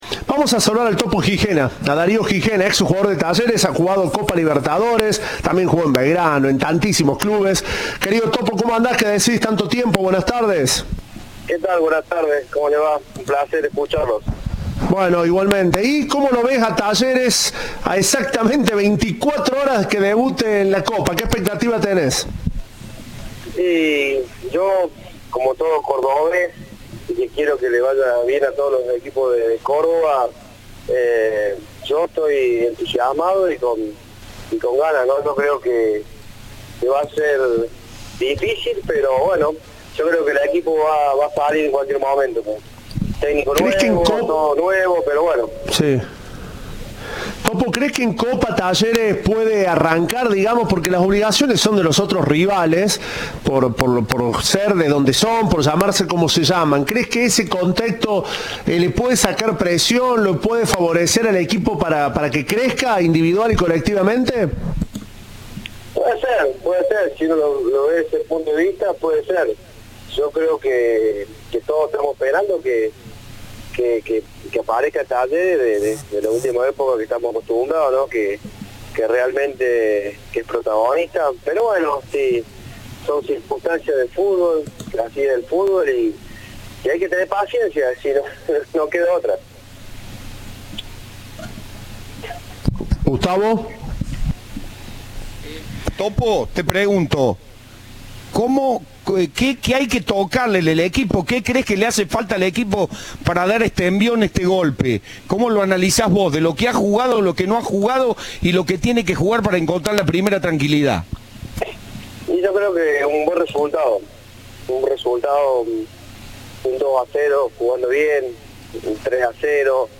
Entrevista de Tiempo de Juego.